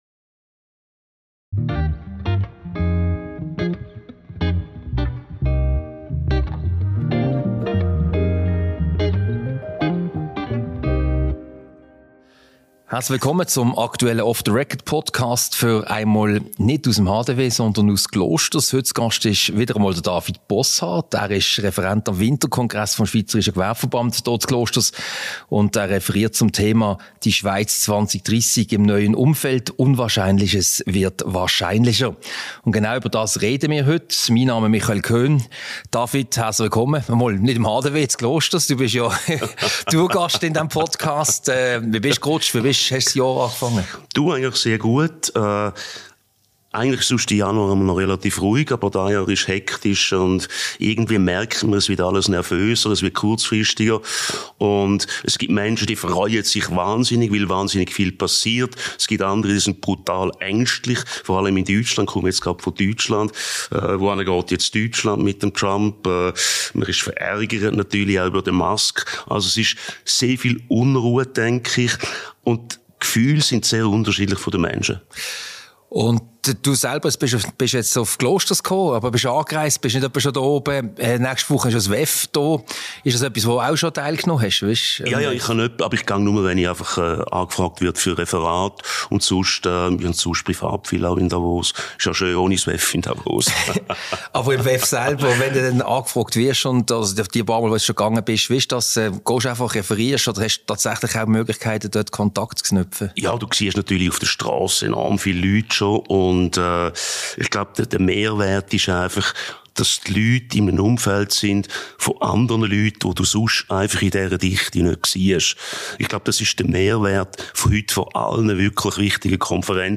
Diese Podcast-Ausgabe wurde als in Klosters aufgezeichnet, anlässlich des Winterkongresses des Schweizerischen Gewerbeverbandes.